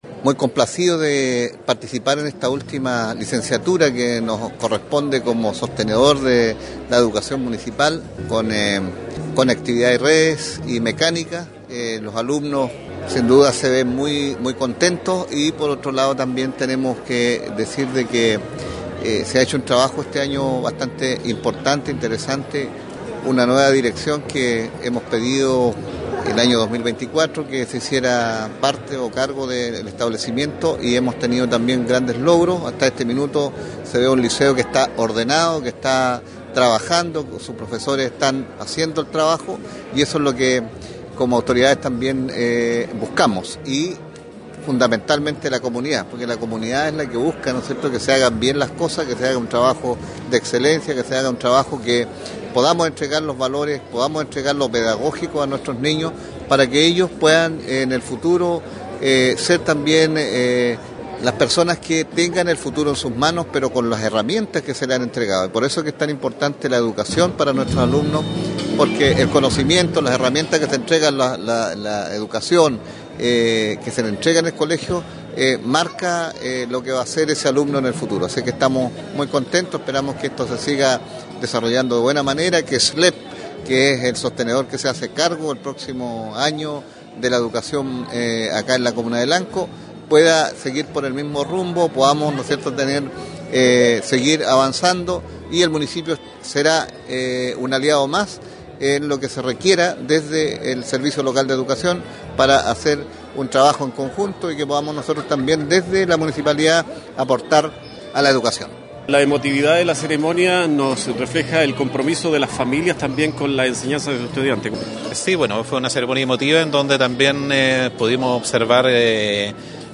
En una emotiva ceremonia realizada en el gimnasio del Liceo República del Brasil de Malalhue, se llevó a cabo la licenciatura de los estudiantes de educación media técnico-profesional de la promoción 2024, quienes egresaron de las especialidades de Mecánica y Conectividad y Redes.
Al cierre de la actividad, el alcalde Juan Rocha Aguilera resaltó la importancia de este hito en la formación de los estudiantes y mencionó que esta licenciatura tiene un significado especial al ser la última bajo la administración municipal del liceo.